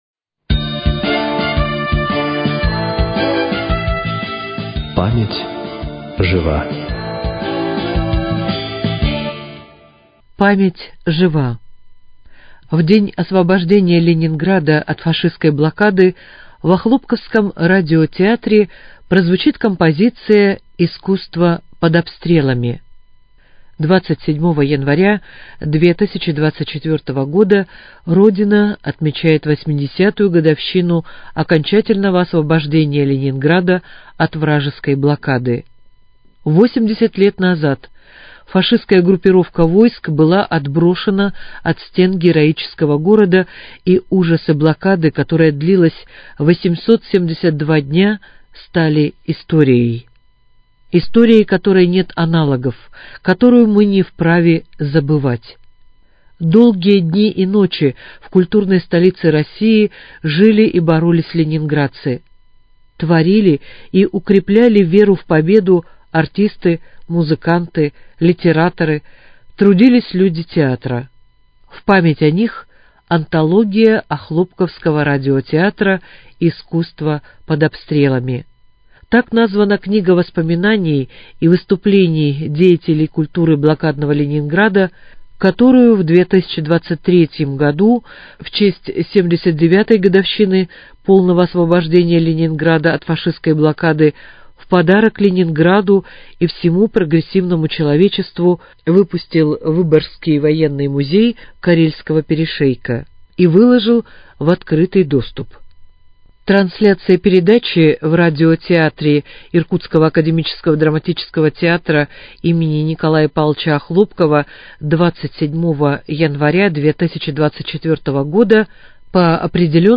читают воспоминания из книги «Искусство под обстрелом»